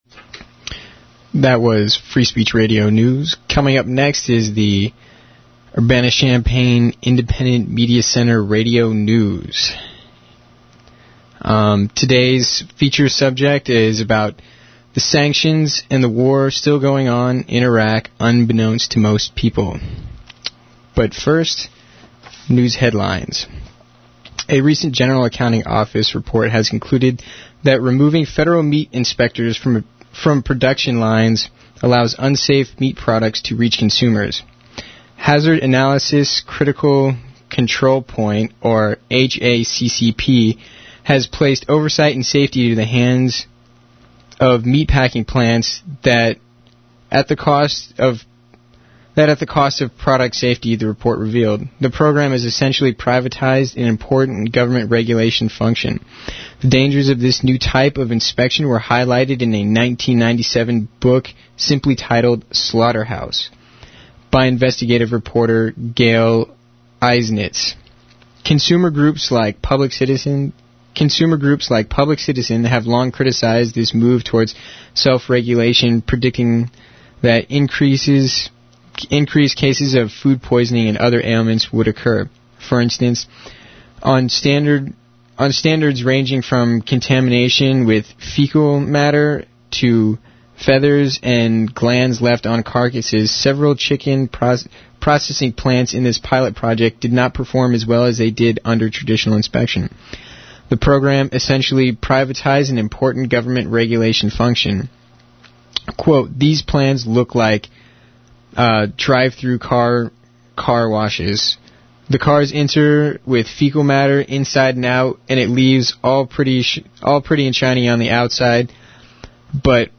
Miscellaneous imc_news1-28-02.mp3 (5314 k) This is the 1-28-02 edition of the IMC's weekly 1/2 hour radio news program, as broadcast live on community radio WEFT 90.1 FM.